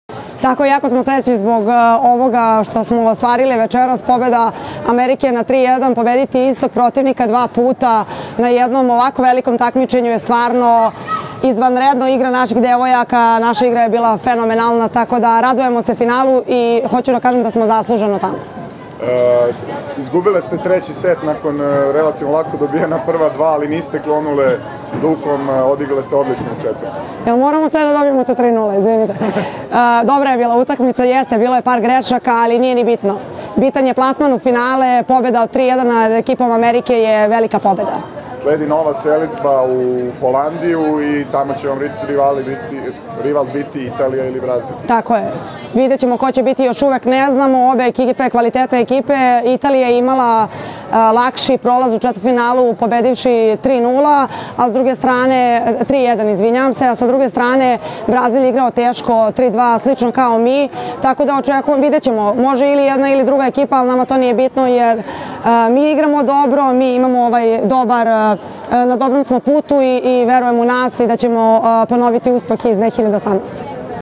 Izjava Teodore Pušić